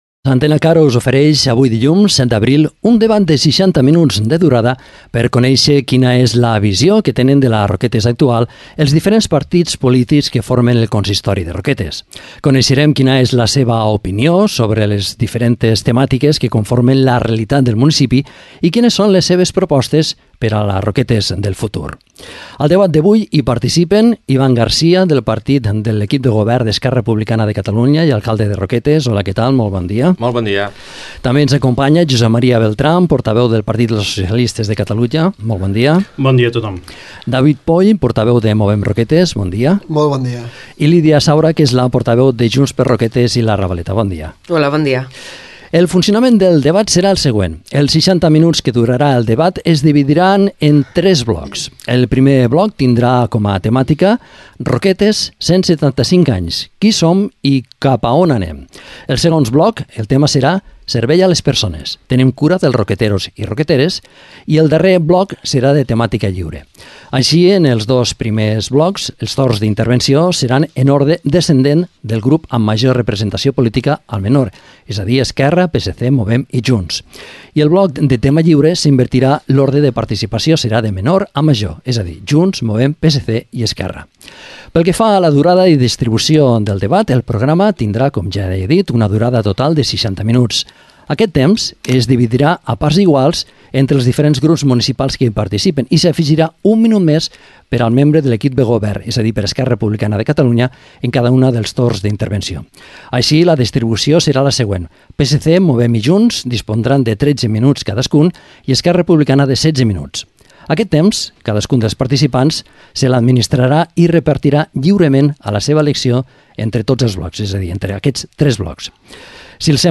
Debat amb els representants dels grups municipals de Roquetes | Antena Caro - Roquetes comunicació